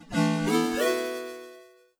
Accept.wav